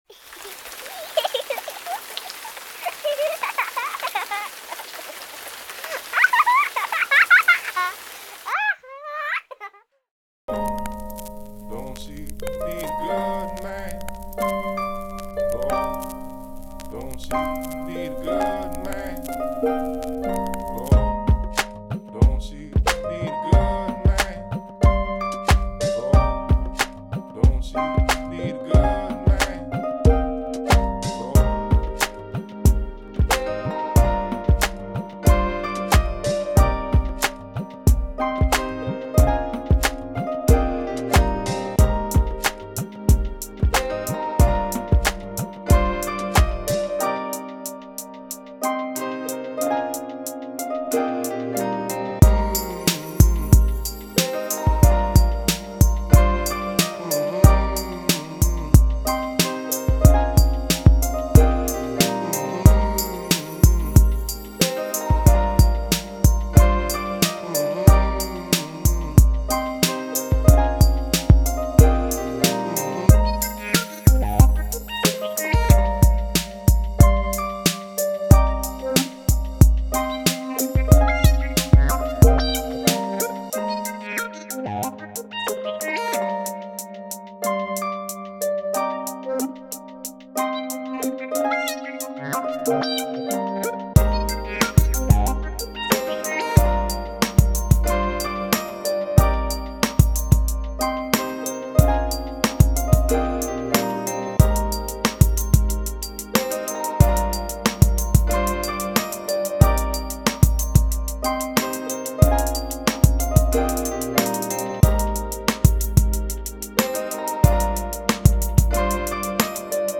Mix of my beats and finished projects....Enjoy!